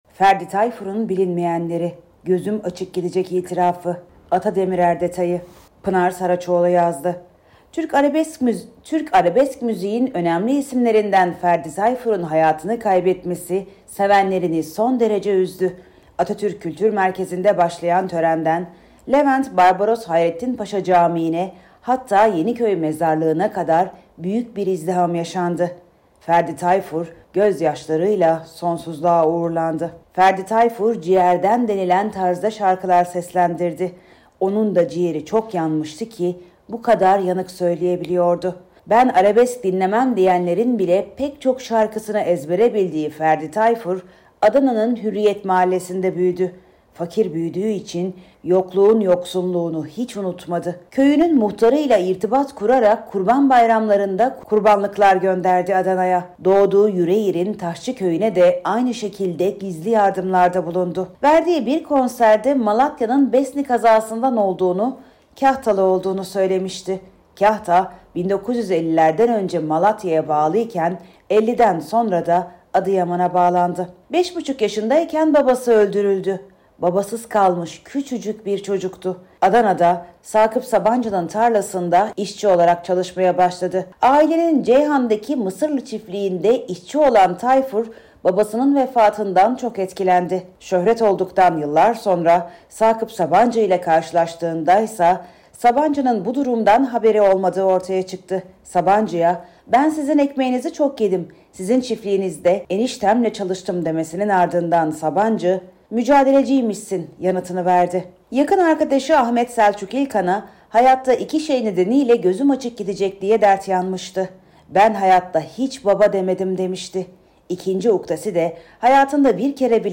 Detaylar Odatv sesli haberde...